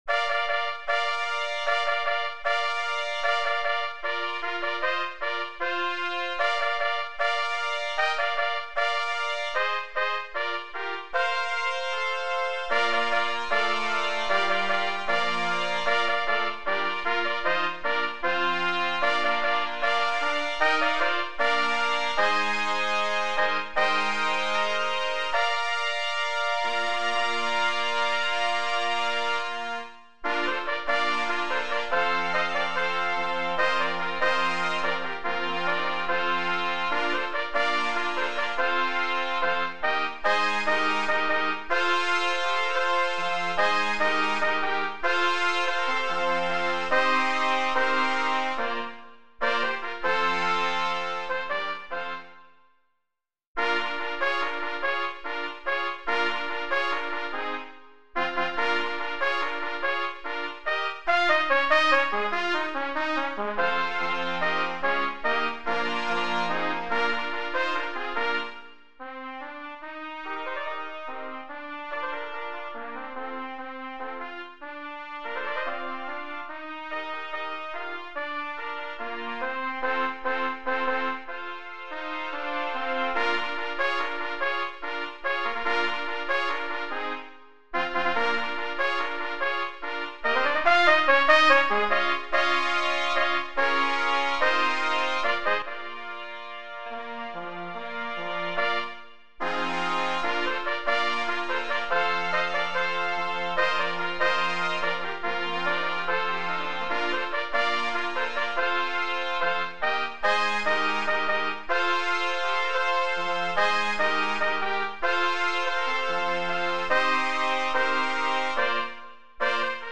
Voicing: Trumpet Sextet